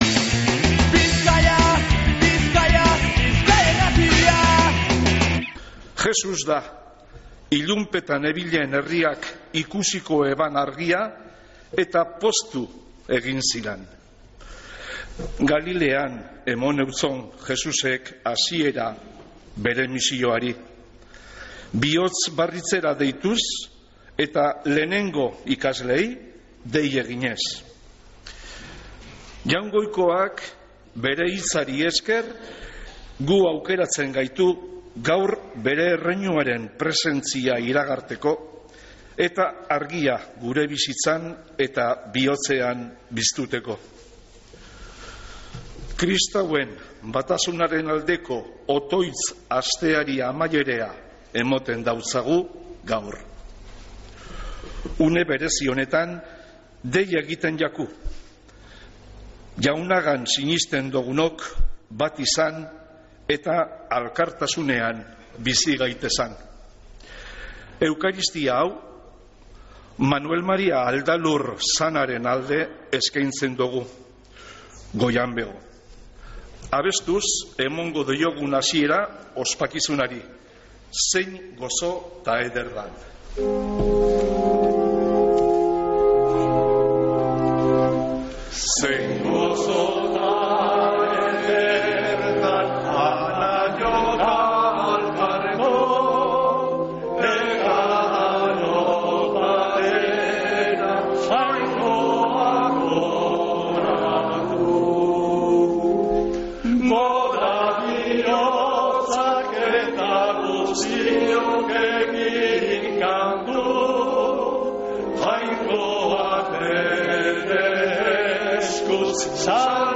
Mezea San Felicisimotik | Bizkaia Irratia